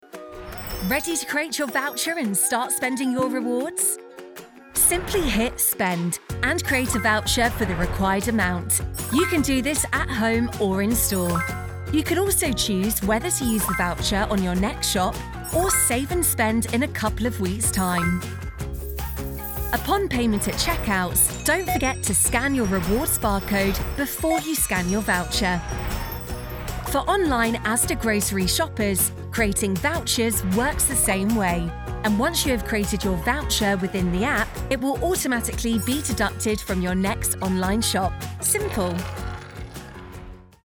Vídeos Explicativos
Sennheiser MK4
Jovem adulto
Mezzo-soprano